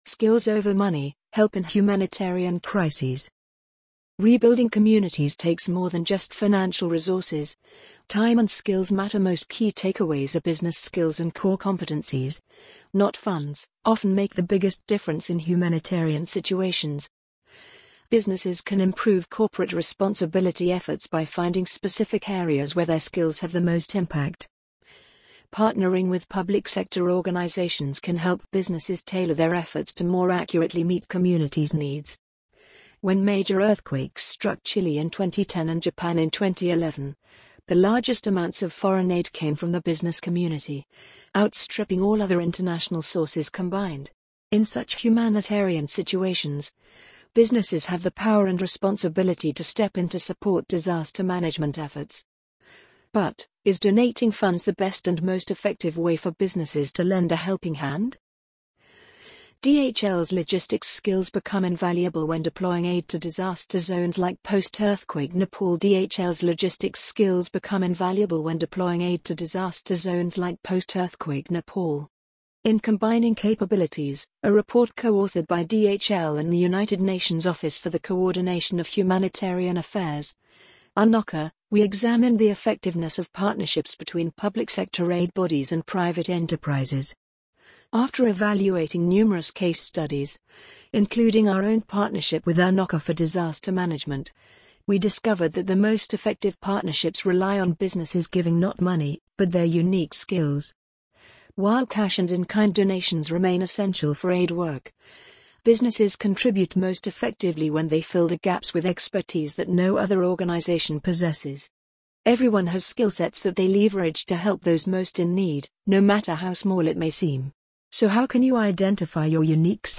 amazon_polly_1625.mp3